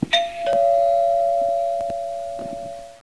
doorbell.aif